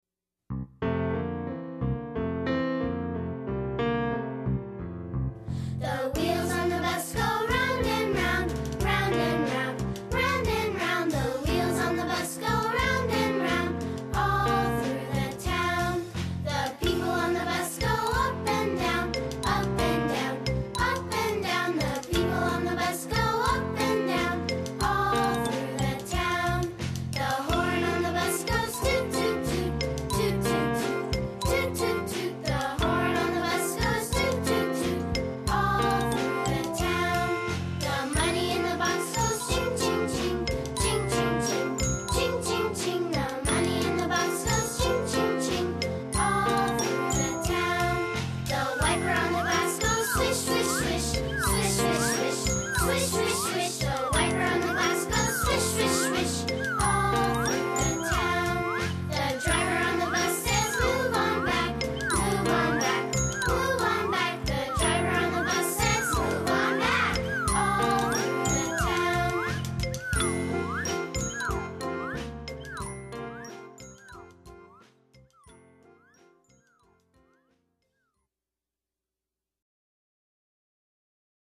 英语童谣